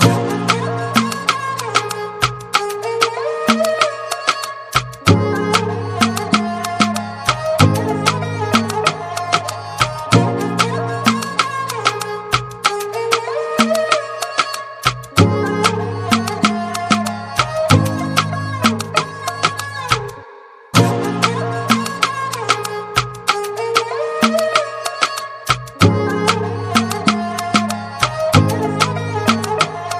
with creative harmony and emotional vocals